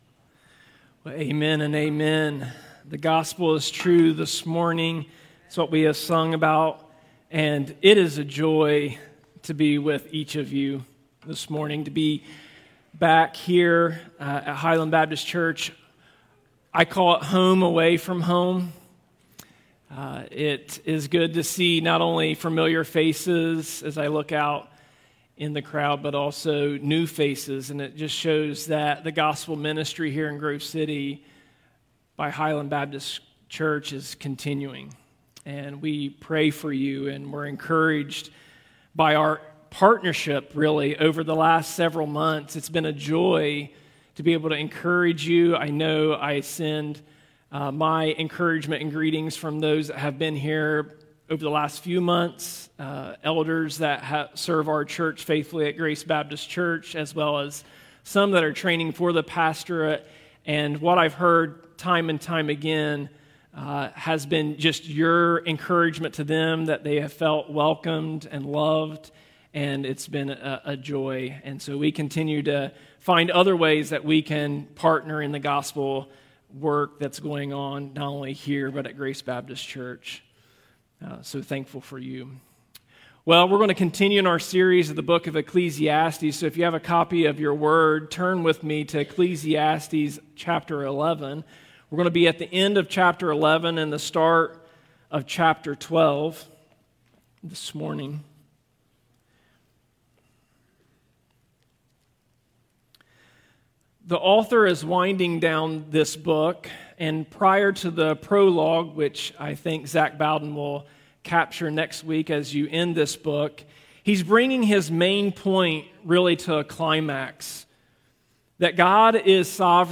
Highland Baptist Church Sermons